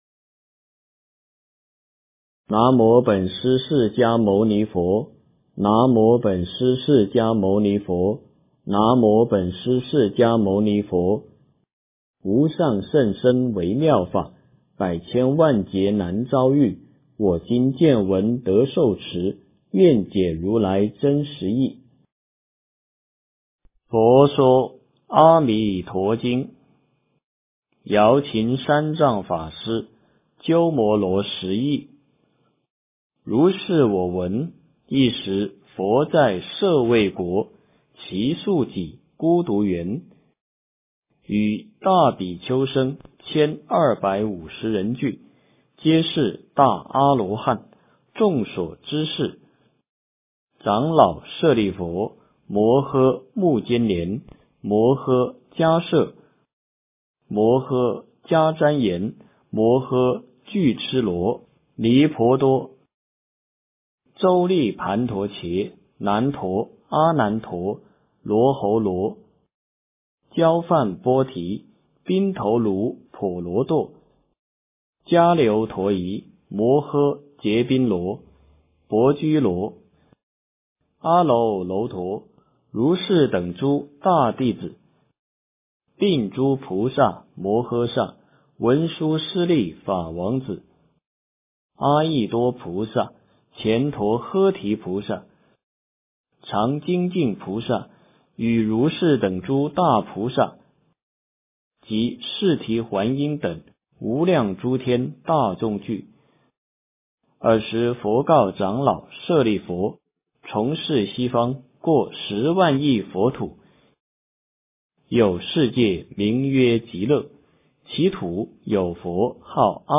佛说阿弥陀经 - 诵经 - 云佛论坛